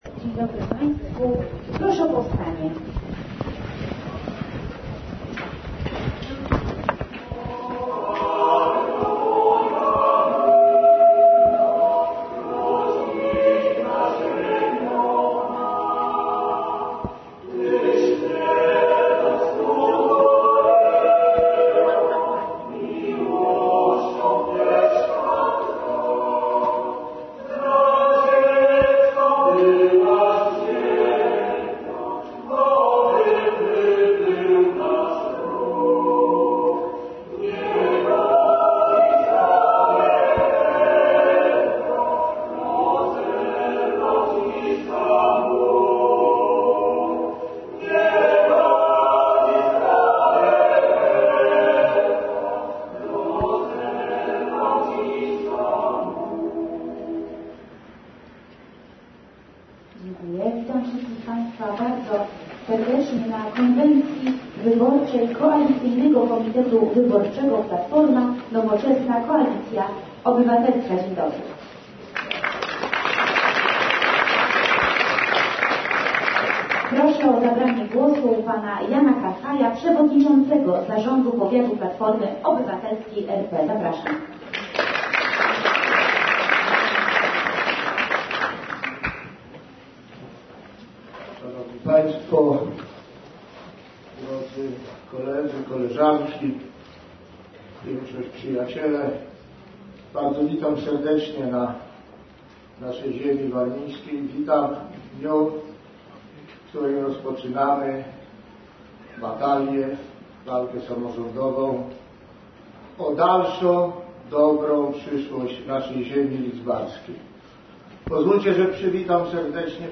konwencja PO.mp3